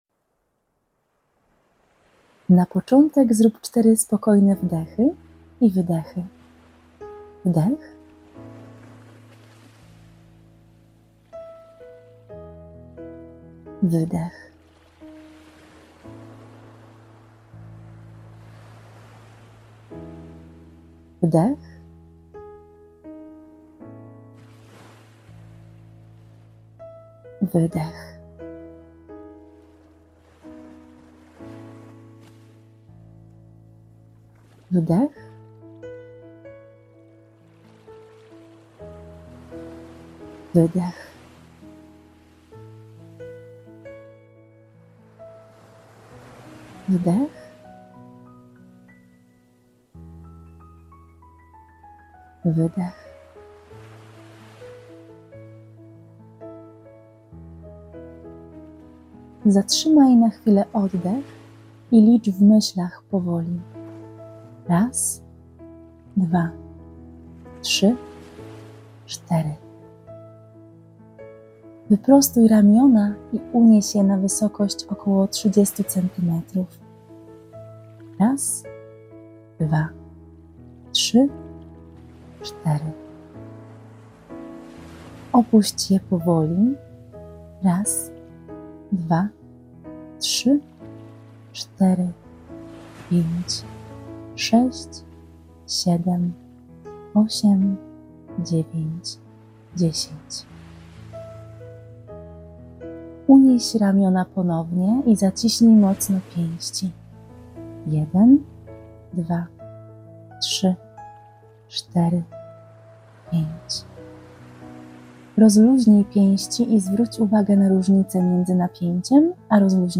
W ramach podziękowania przygotowałam dla Ciebie relaksację opartą na treningu progresywnej relaksacji Jacobsona połączeniu z muzyką relaksacyjną.